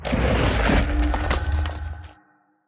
Minecraft Version Minecraft Version latest Latest Release | Latest Snapshot latest / assets / minecraft / sounds / block / respawn_anchor / set_spawn2.ogg Compare With Compare With Latest Release | Latest Snapshot